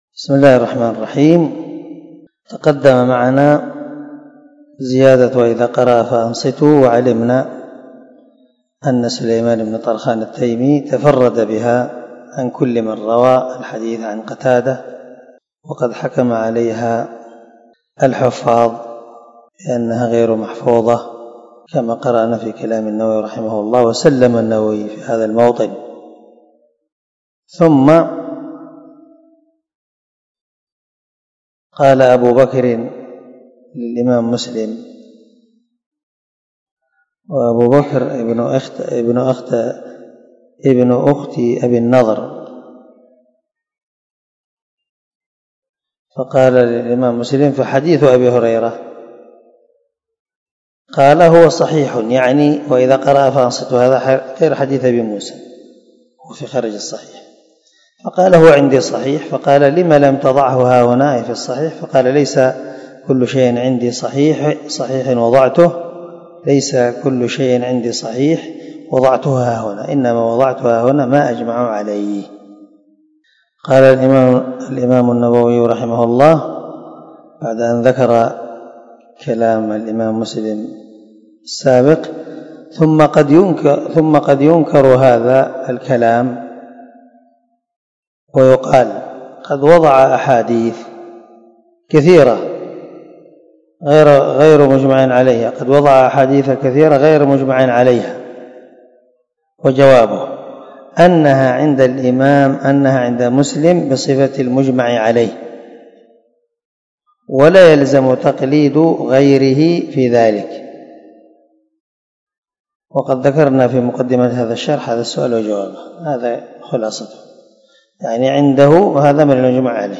280الدرس 24 من شرح كتاب الصلاة تابع حديث رقم ( 403 – 404 ) من صحيح مسلم